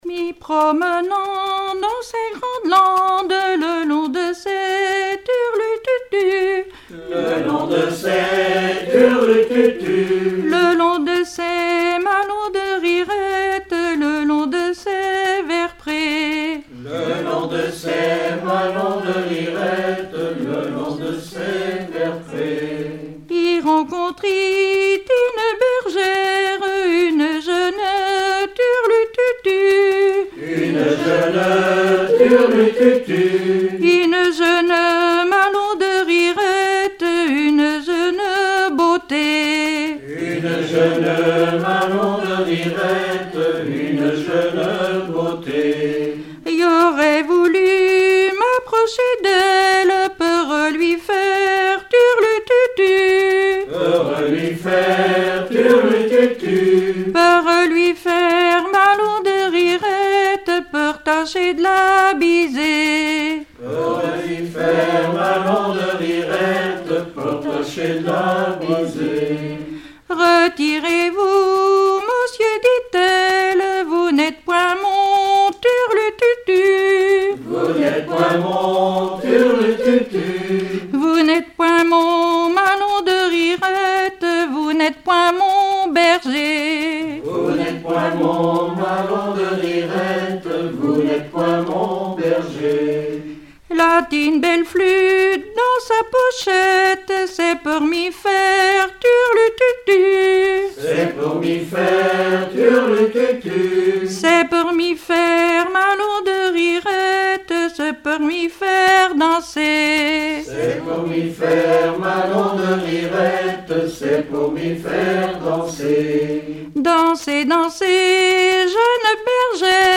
Genre laisse
Collectif de chanteurs du canton - veillée (2ème prise de son)
Pièce musicale inédite